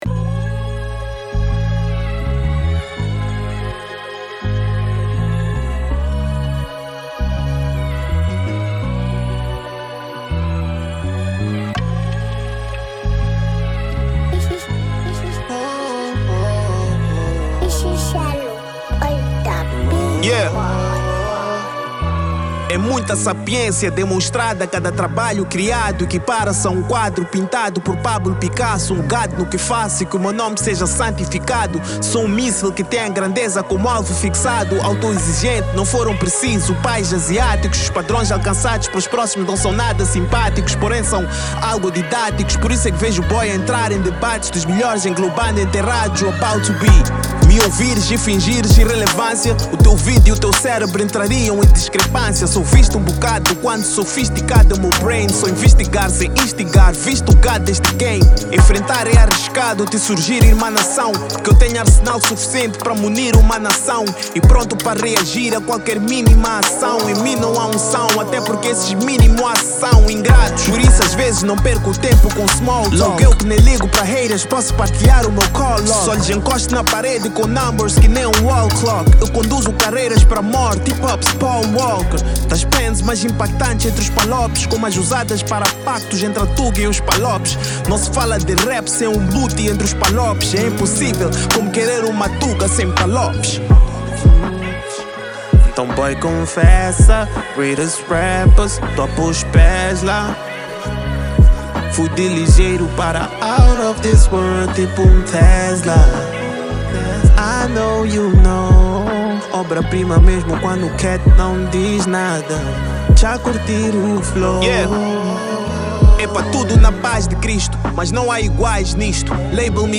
Genero: Trap